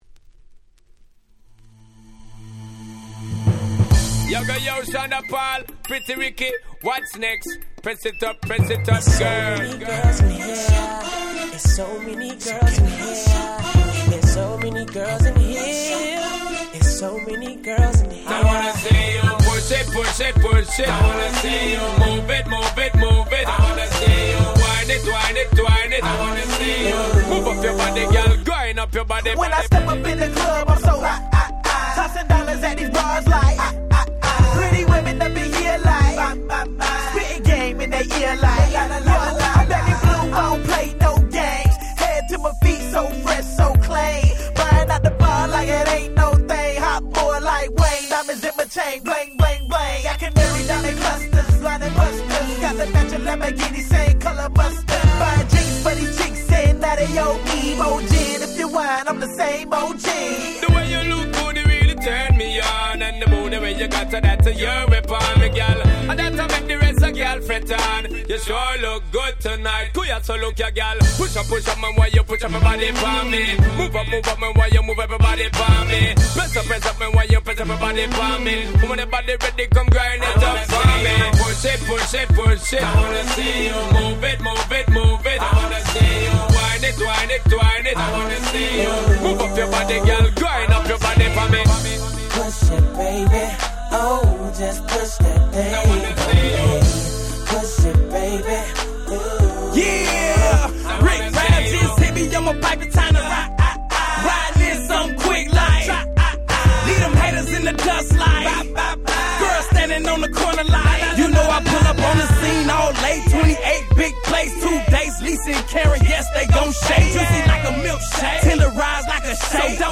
07' Smash Hit R&B / Hip Hop !!